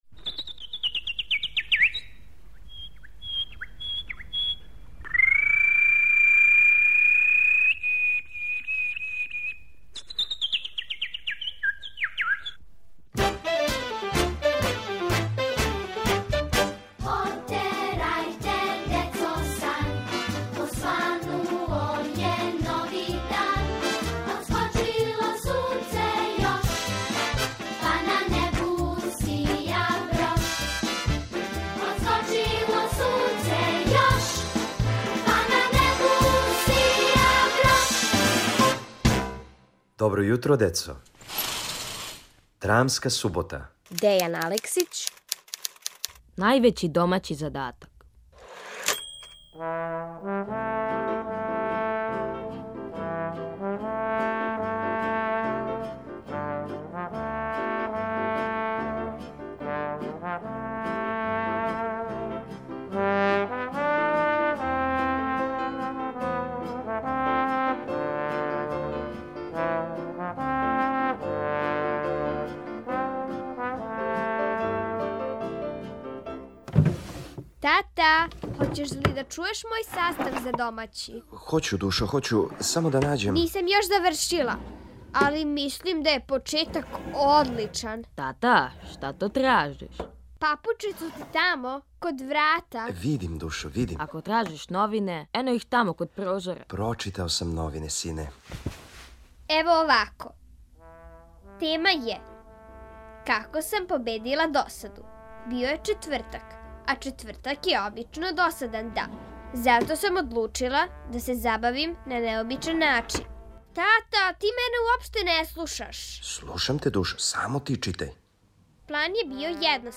Сазнајте где су један дечак и једна девојчица сакрили татин омиљени предмет, у краткој драми Дејана Алексића.